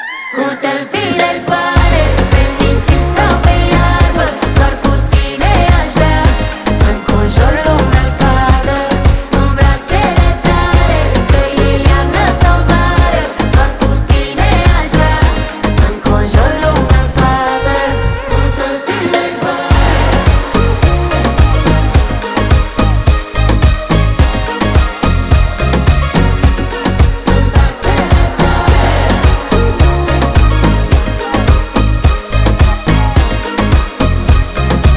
2025-07-03 Жанр: Поп музыка Длительность